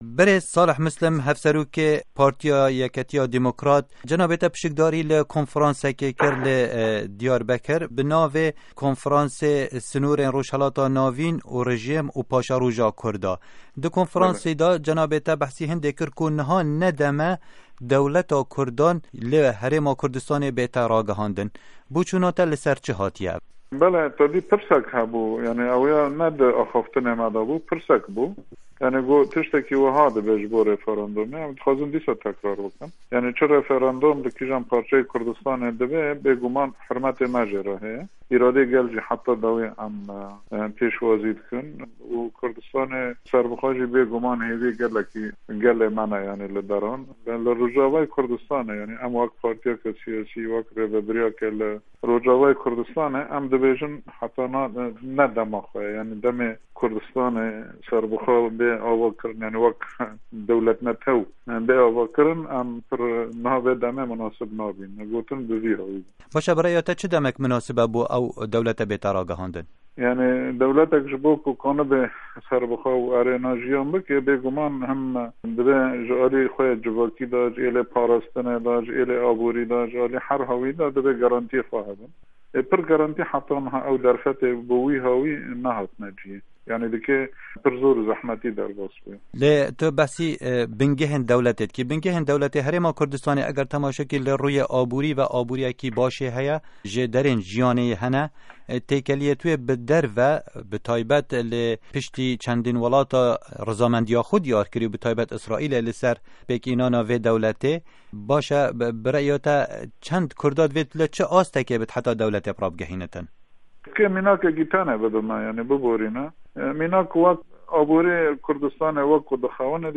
Hevpeyvîn bi Salih Mislim re